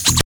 13 SCRATCH 2.wav